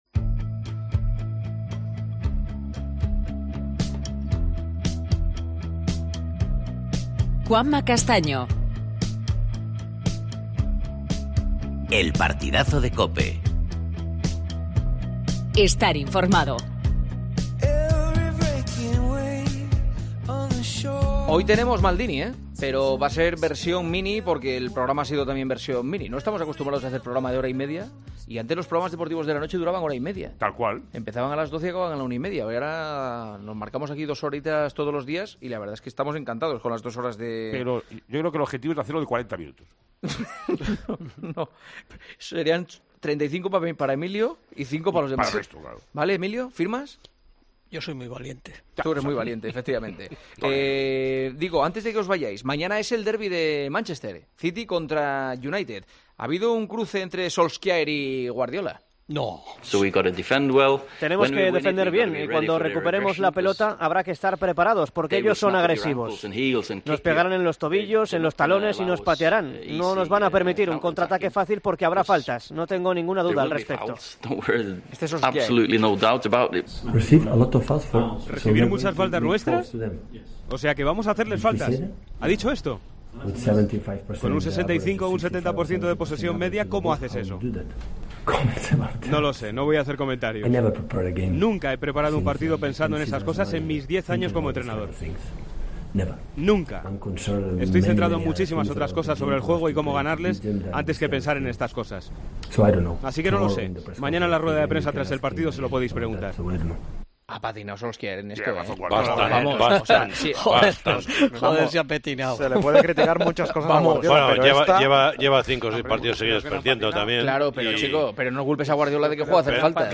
AUDIO: El Real Madrid, clasificado para la Final Four de la Euroliga. Entrevista a Campazzo.